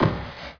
drag4.wav